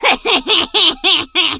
diva_kill_vo_07.ogg